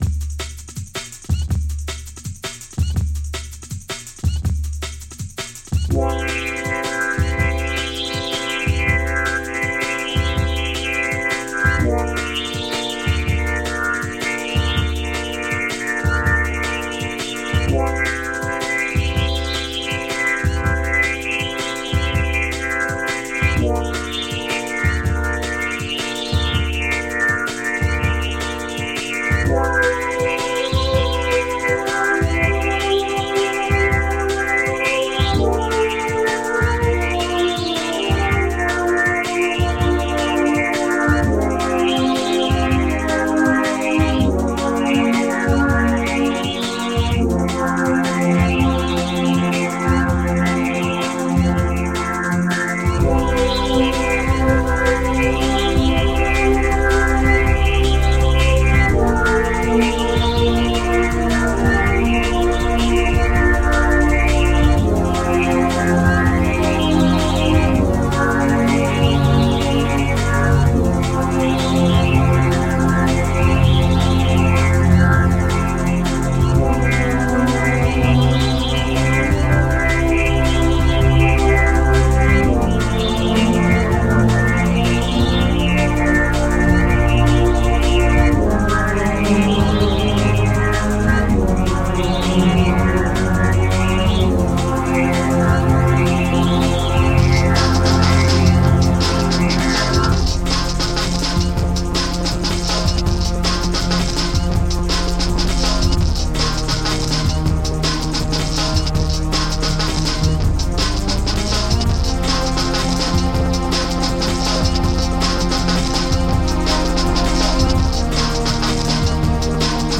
This version triggers my tinnitus something fierce.